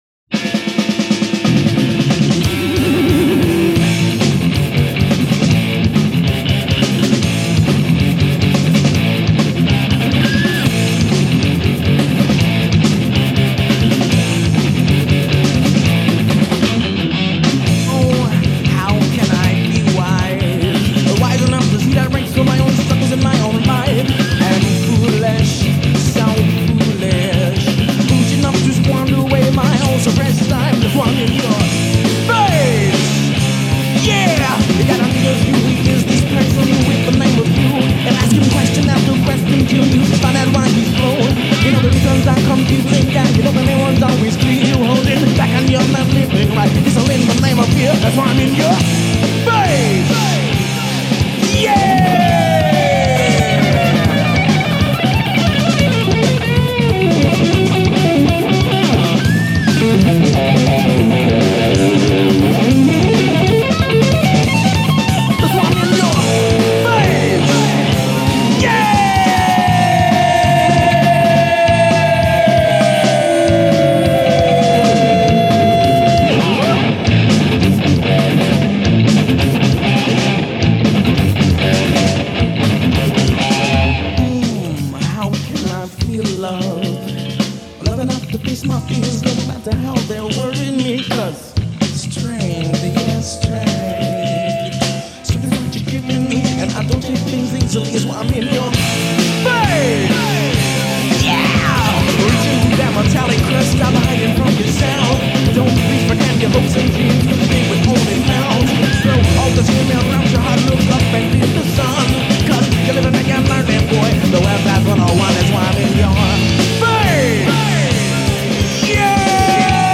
Most of the tracks are first takes.